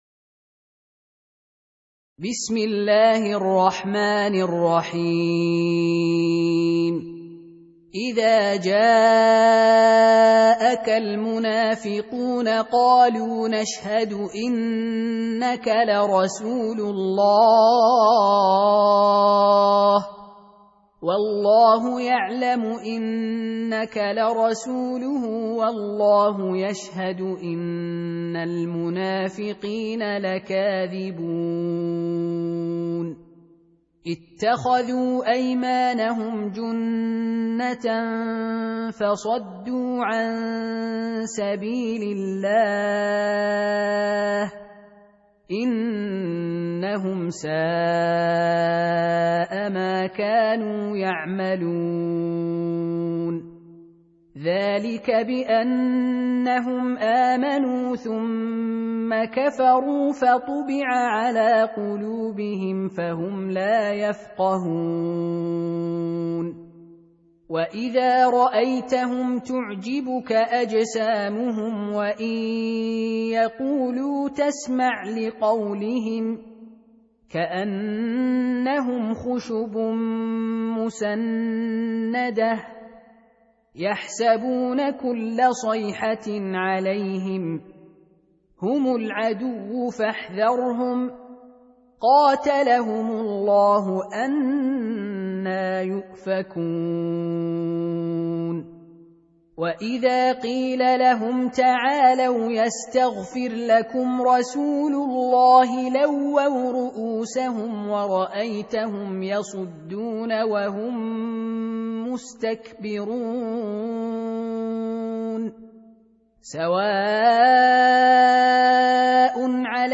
Surah Repeating تكرار السورة Download Surah حمّل السورة Reciting Murattalah Audio for 63. Surah Al-Munafiq�n سورة المنافقون N.B *Surah Includes Al-Basmalah Reciters Sequents تتابع التلاوات Reciters Repeats تكرار التلاوات